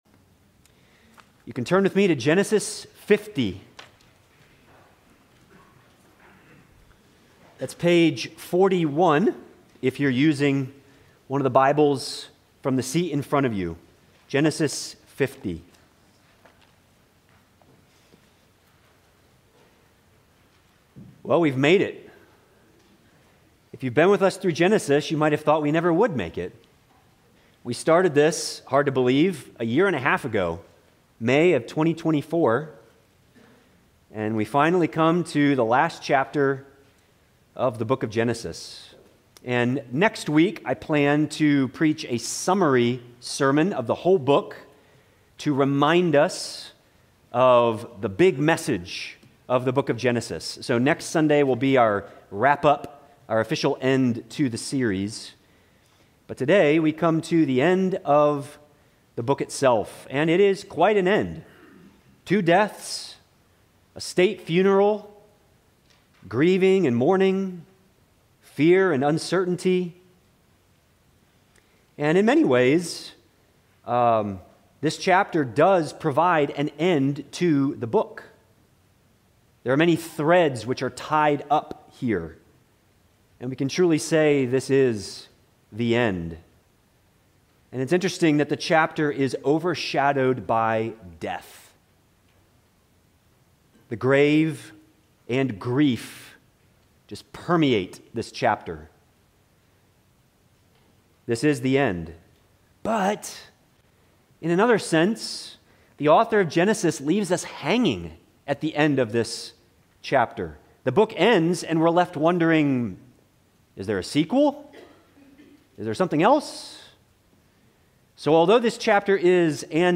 Sermons – Bethany Baptist Church Brevard, NC